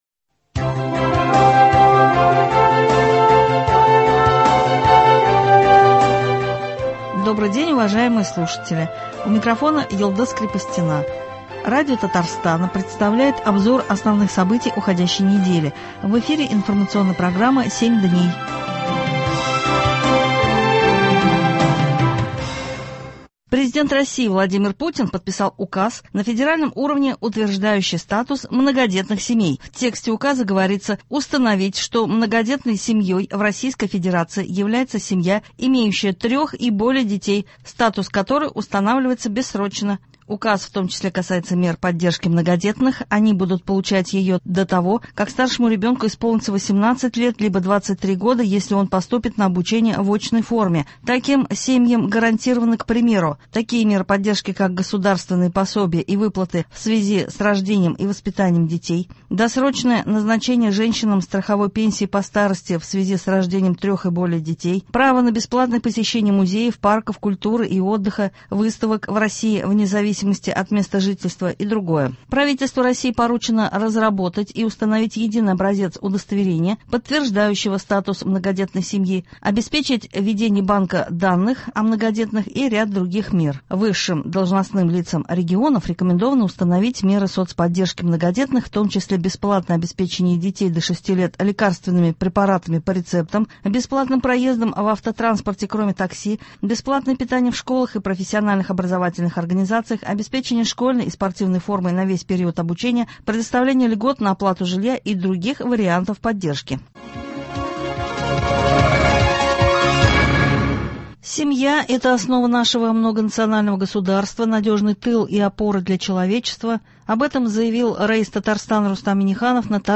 Обзор событий. Продолжается подготовка к выборам Президента РФ. Прошли церемонии открытия Года Семьи.